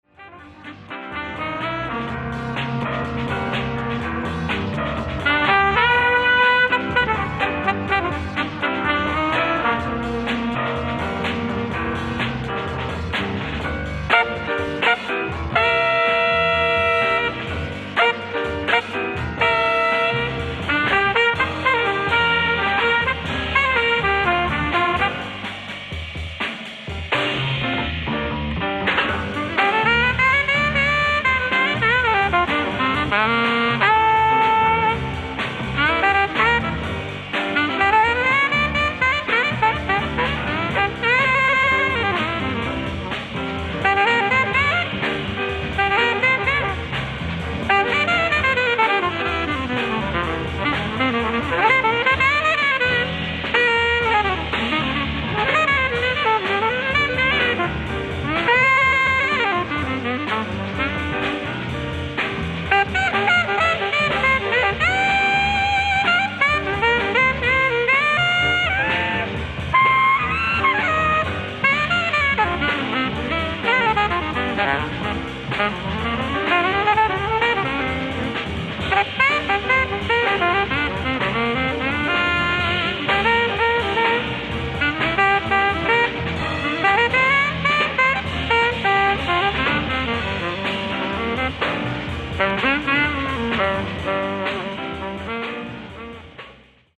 ライブ・アット・NDR スタジオ01, ハンブルグ、ドイツ 03/19/1969
※試聴用に実際より音質を落としています。